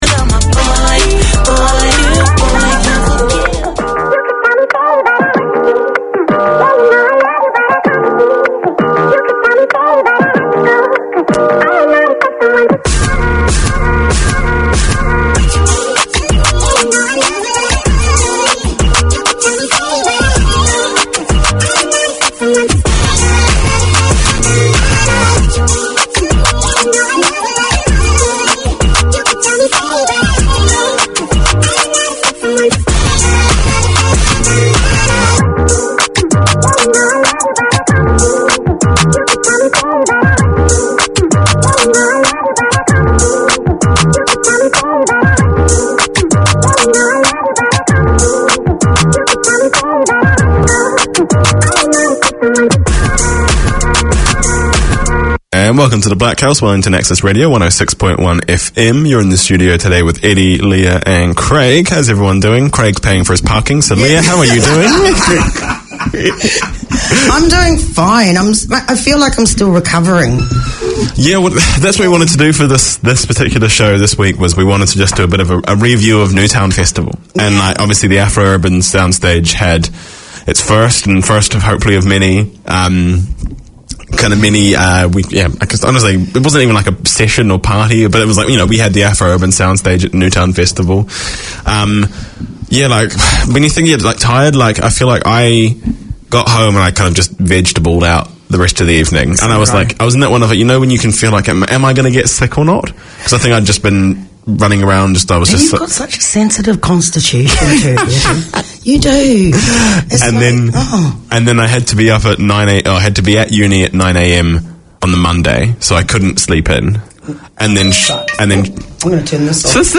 In this one-off special, Planet FM presents a snapshot of Te Matatini 2023, with vox-pops and interviews undertaken in the marketplace at Ana Wai / Eden Park where the festival took place. A celebration of the best of Kapa Haka across Aotearoa New Zealand, this year's Te Matatini festival was hosted by Ngāti Whātua Ōrākei.